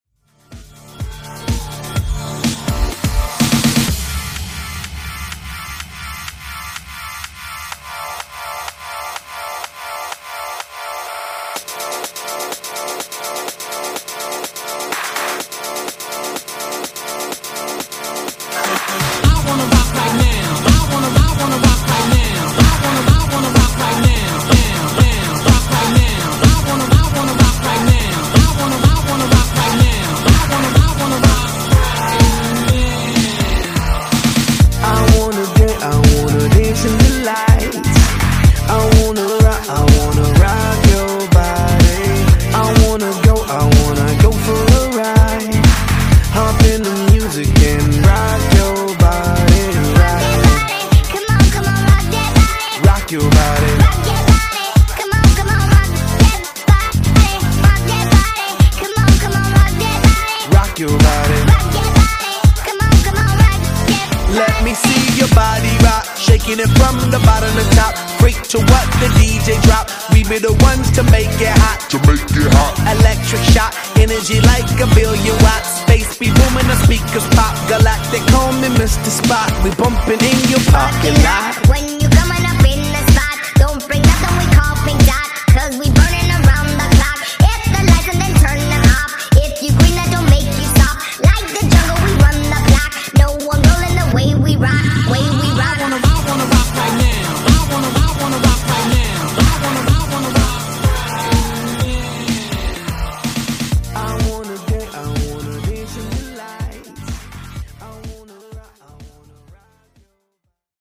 Genres: R & B , RE-DRUM
Dirty BPM: 79 Time